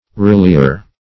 relier - definition of relier - synonyms, pronunciation, spelling from Free Dictionary Search Result for " relier" : The Collaborative International Dictionary of English v.0.48: Relier \Re*li"er\ (r?-l?"?r), n. [From Rely .]